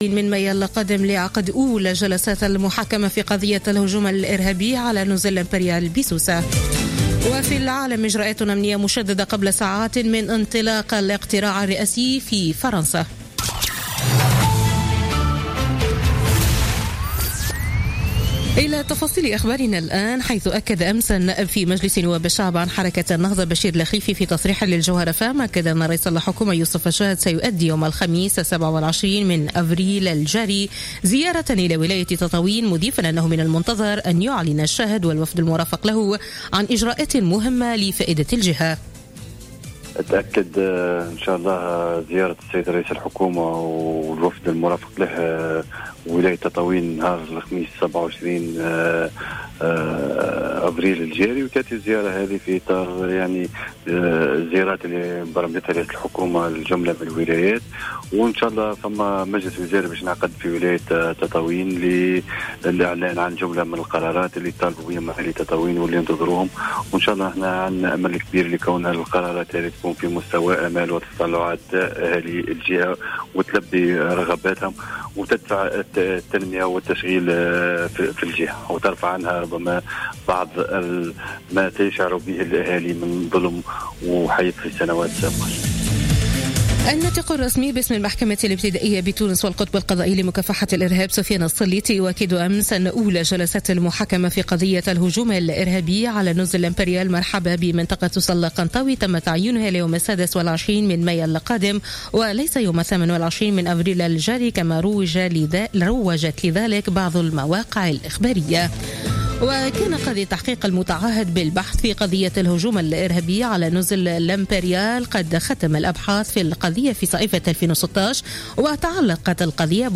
نشرة أخبار منتصف الليل ليوم الأحد 23 أفريل 2017